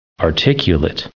added pronounciation and merriam webster audio
79_articulate.ogg